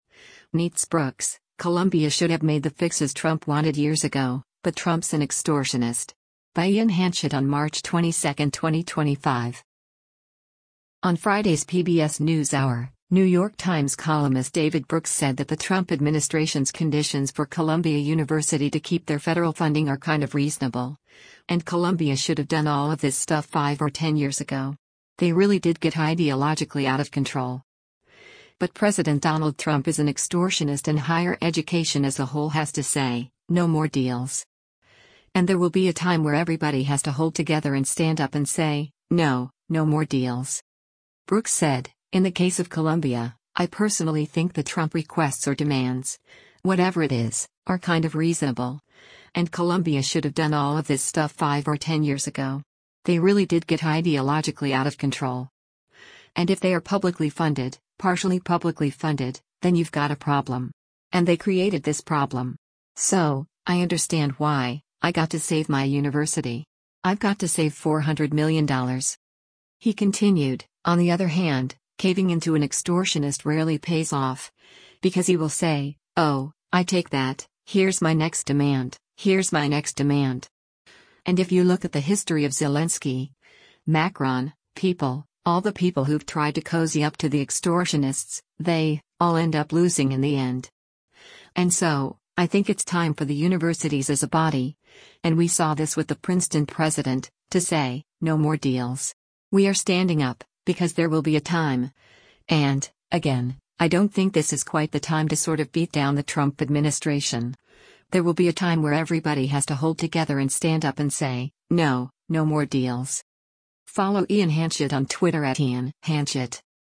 On Friday’s “PBS NewsHour,” New York Times columnist David Brooks said that the Trump administration’s conditions for Columbia University to keep their federal funding “are kind of reasonable, and Columbia should have done all of this stuff five or ten years ago. They really did get ideologically out of control.” But President Donald Trump is an “extortionist” and higher education as a whole has to say, “no more deals.” And “there will be a time where everybody has to hold together and stand up and say, no, no more deals.”